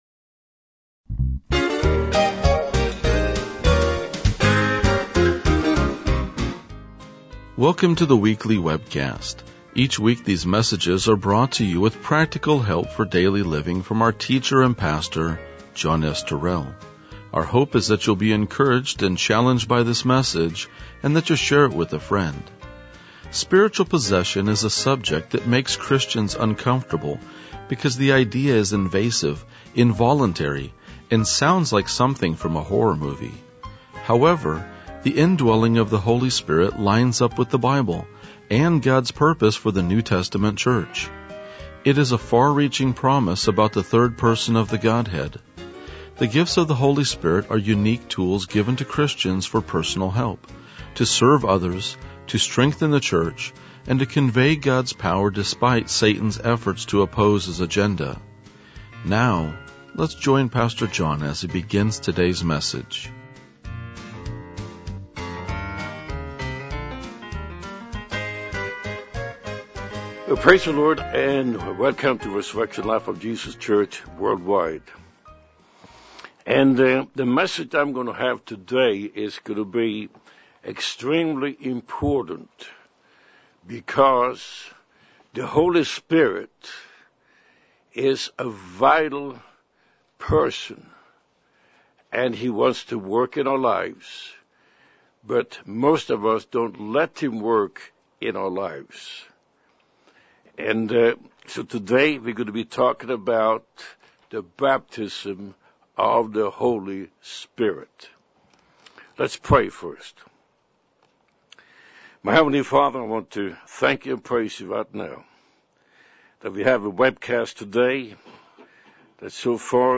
RLJ-2017-Sermon.mp3